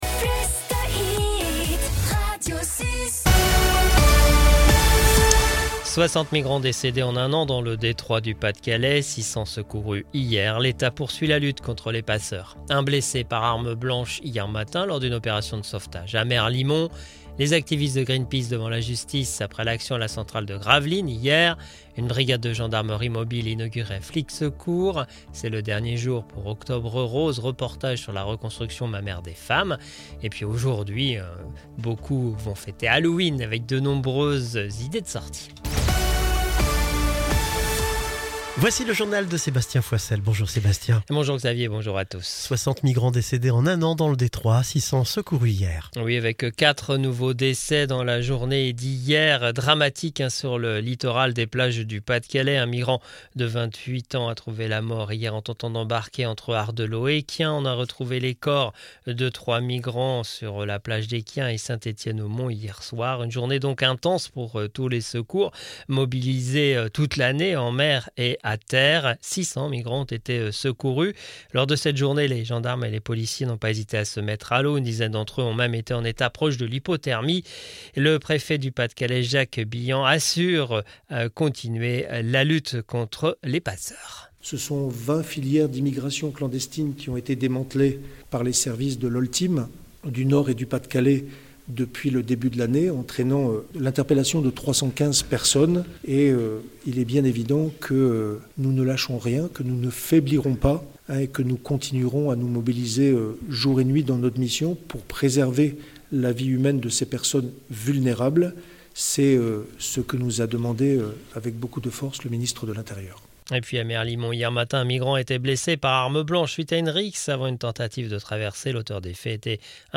Le journal du jeudi 31 octobre 2024